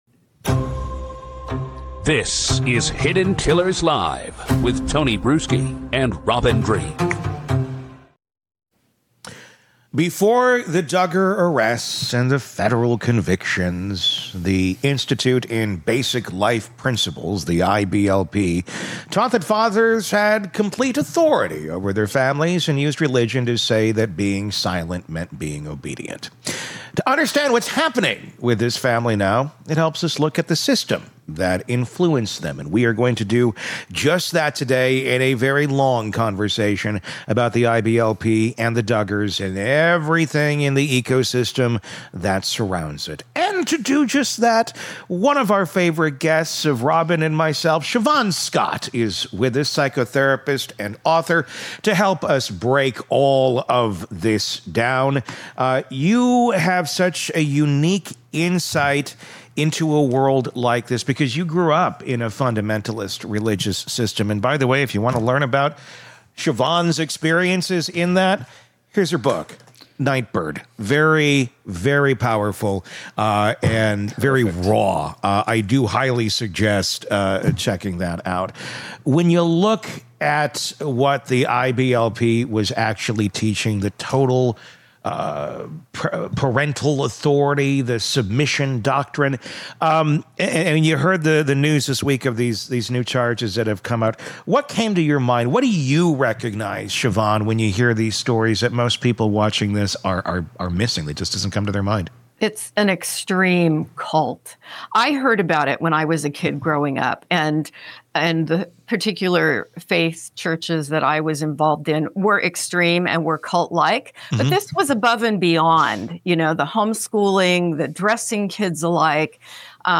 interview series